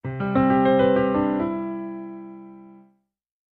Music Logo; Piano Tinkles Only.